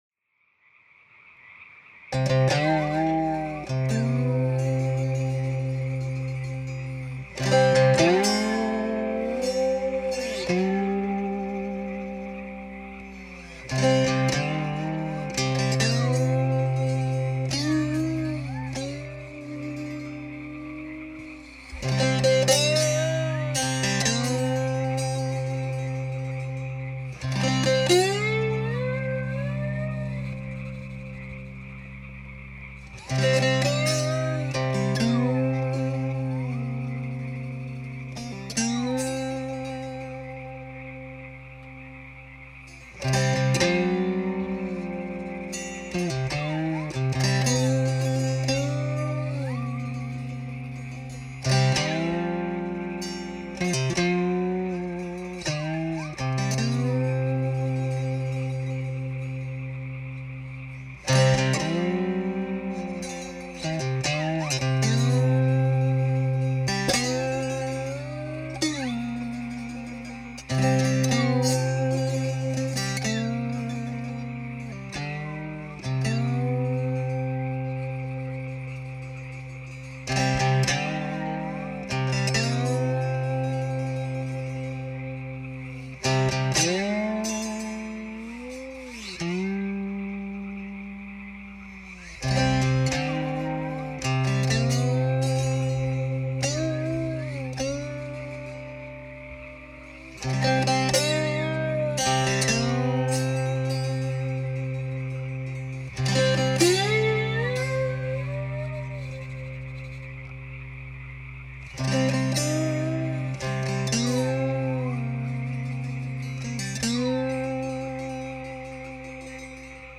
Roots-Blues
was recorded  in North Mississippi in a barn, on a